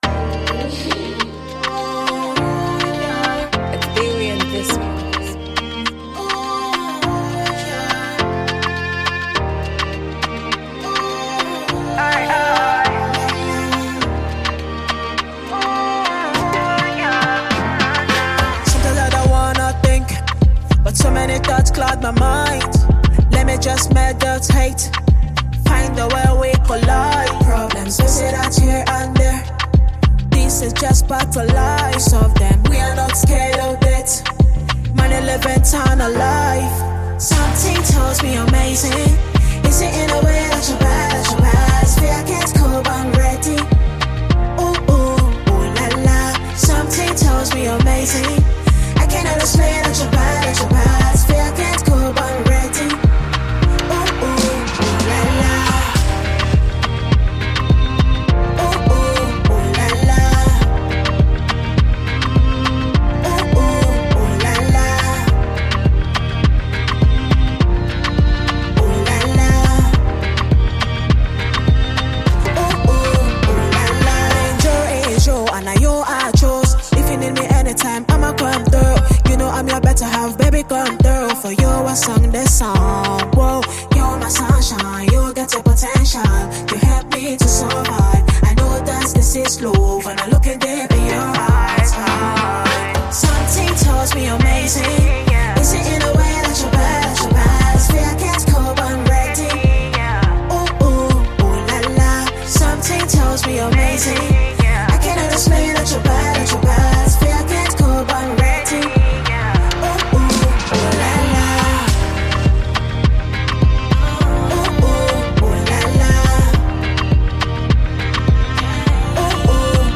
Through heartfelt lyrics and a captivating melody
a powerful anthem